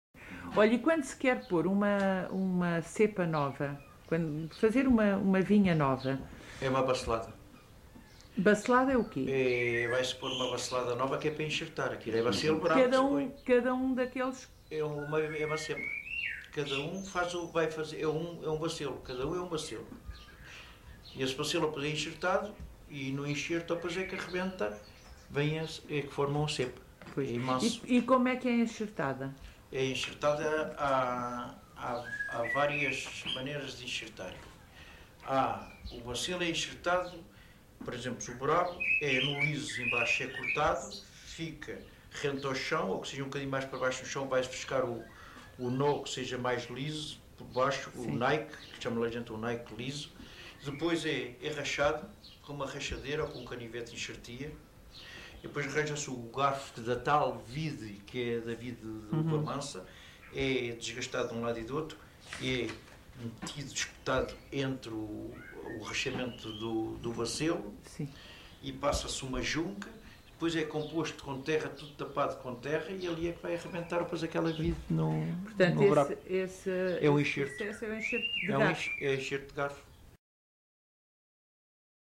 Enxara do Bispo, excerto 22
LocalidadeEnxara do Bispo (Mafra, Lisboa)